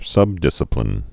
(sŭbdĭsə-plĭn)